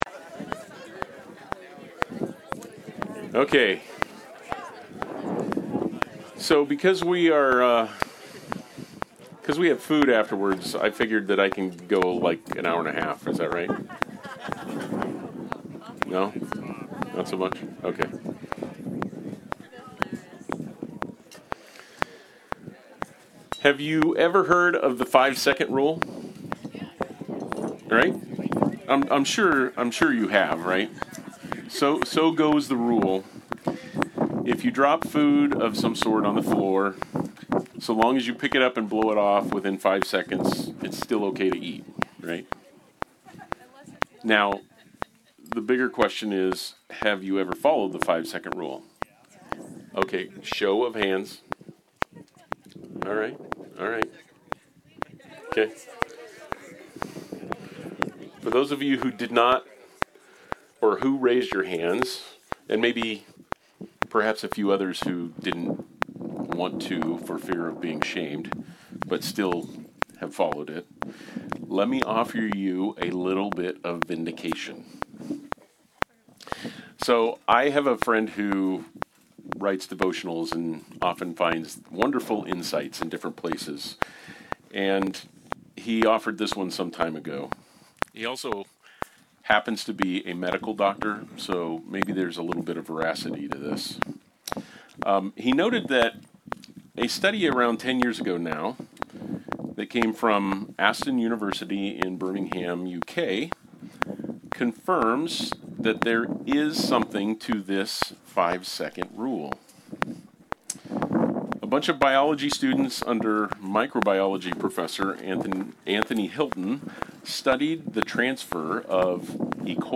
Church on the Farm 2025
Service Type: Sunday Service